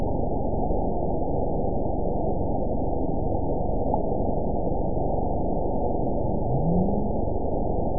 event 912104 date 03/18/22 time 13:57:46 GMT (3 years, 2 months ago) score 9.15 location TSS-AB03 detected by nrw target species NRW annotations +NRW Spectrogram: Frequency (kHz) vs. Time (s) audio not available .wav